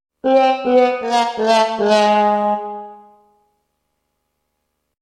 Звук грусти на трубе (фэйл)